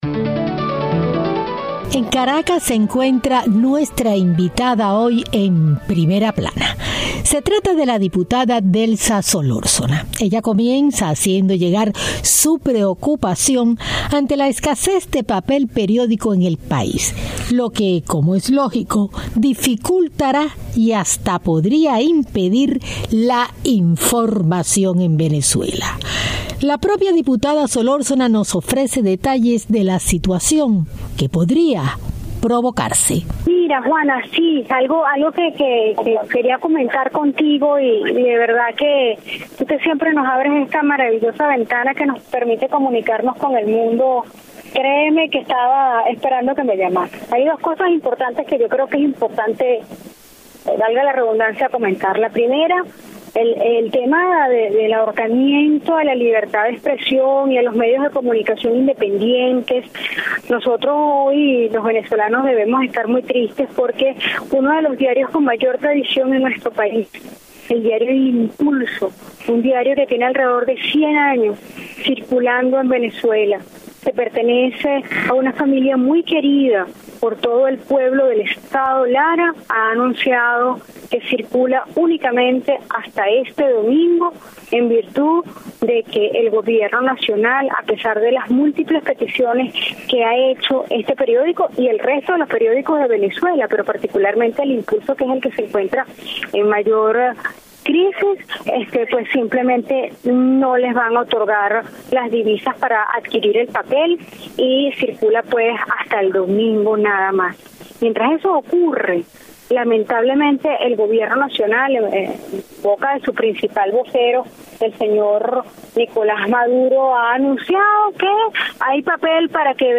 Entrevista con la Diputada venezolana, por la oposicion, Delsa Solorzano, quien analiza la situacion economica y politica de Venezuela./